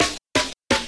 Technique 2: Pitch Shifting
In the examples below I pitched the snare down by 2, then by 4 semitones.